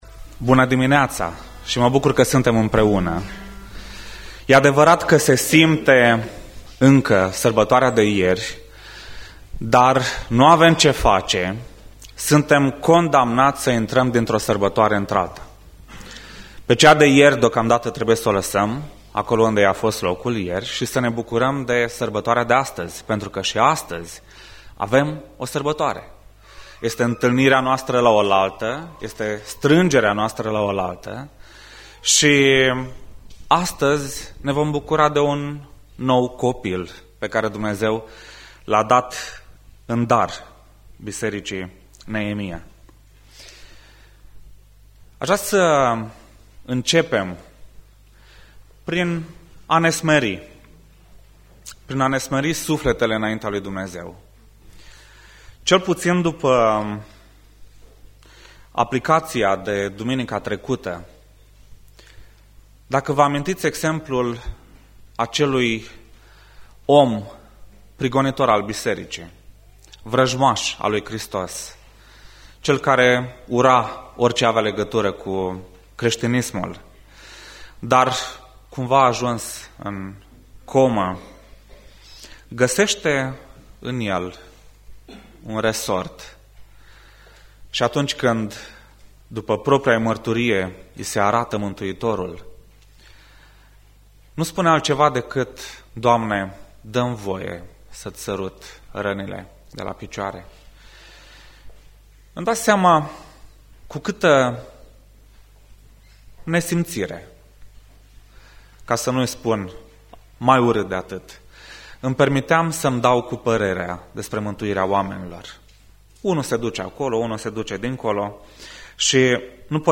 Biserica Neemia - Portal materiale - Predica Aplicatie Ieremia 33